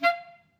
DCClar_stac_F4_v2_rr1_sum.wav